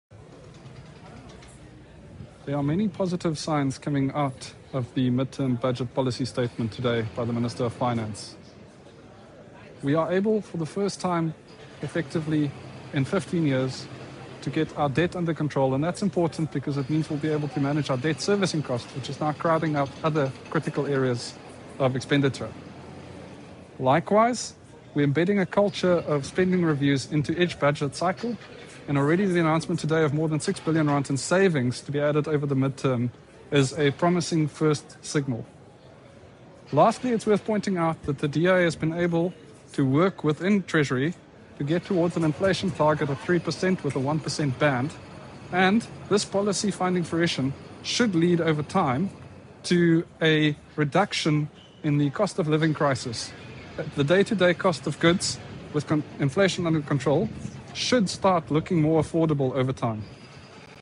Soundbite by Dr Mark Burke MP.